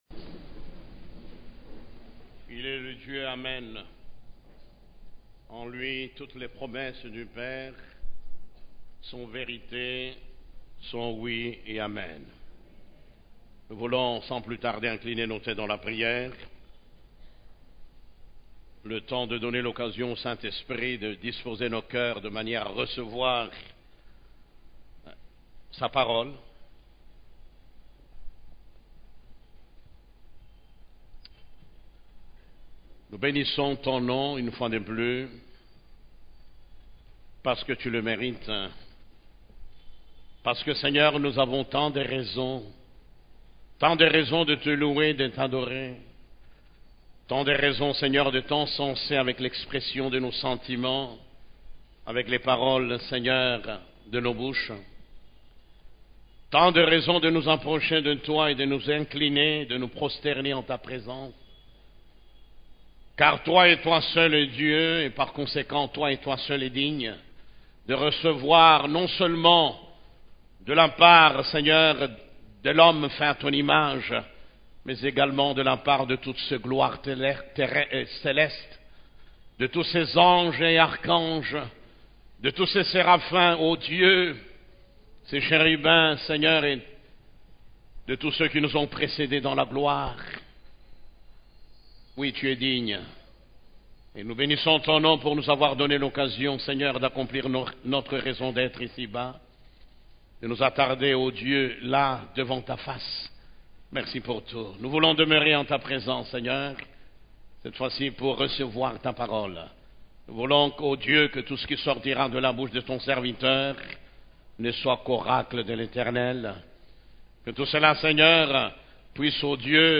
Culte du Dimanche